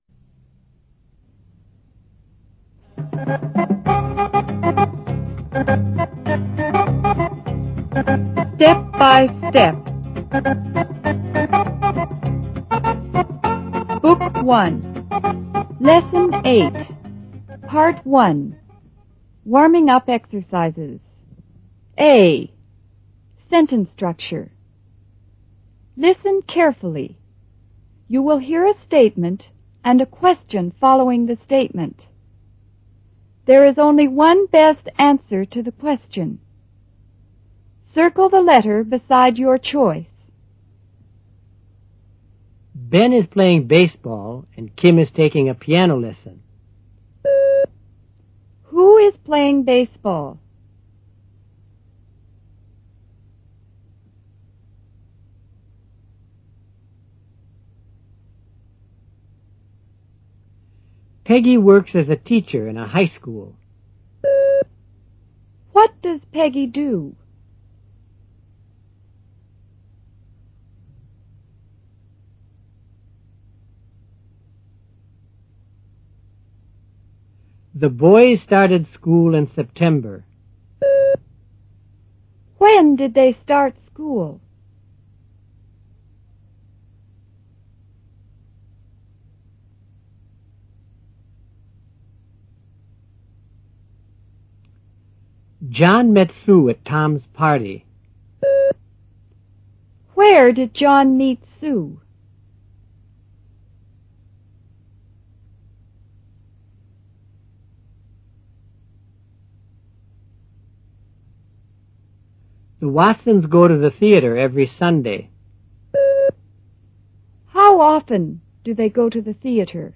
You will hear a statement and a question following the statement.
Directions: You are going to hear some sentences chosen from the comprehension material in this lesson.